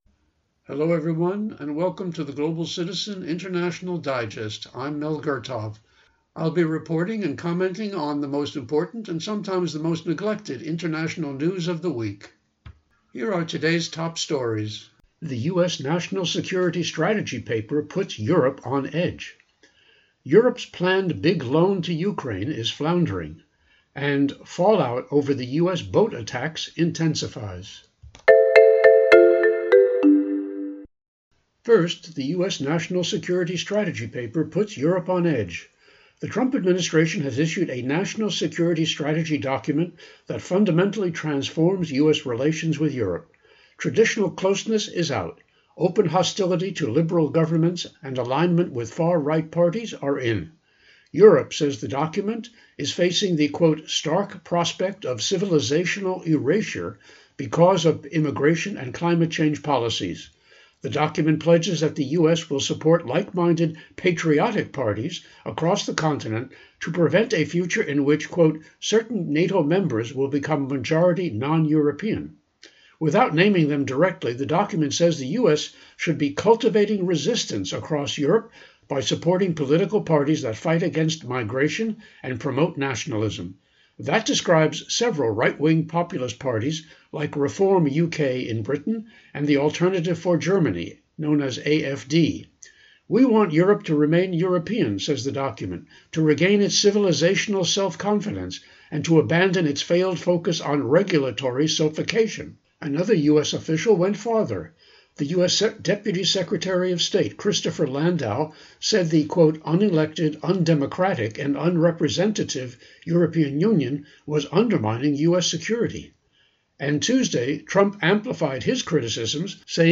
Genre(s): Public Affairs